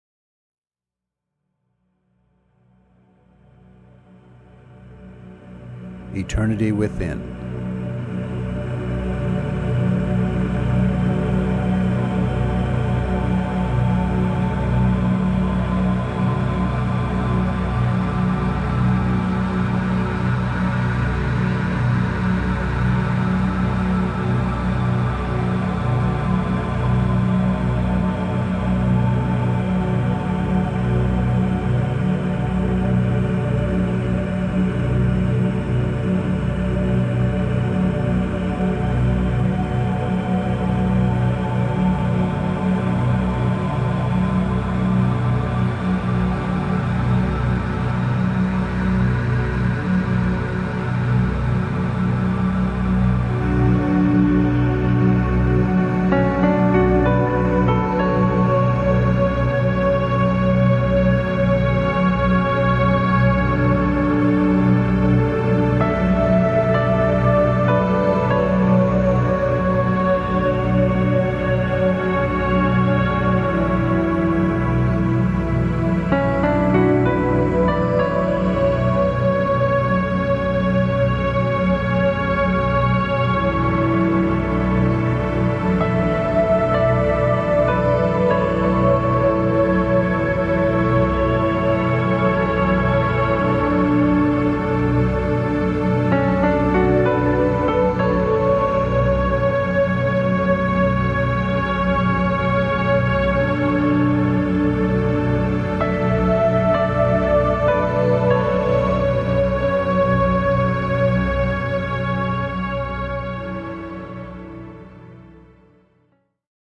使用楽器：アナログ及び、デジタルシンセサイザー　４５分